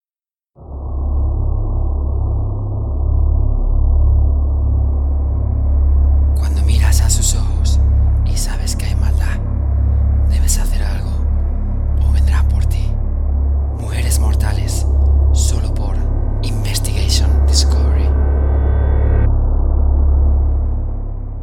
Young voice, capable to adapt to different styles and tones. Voz juvenil, capaz de adaptarse a diferentes estilos y tonos.
kastilisch
Sprechprobe: eLearning (Muttersprache):
anuncio-terror-final-con-musica.mp3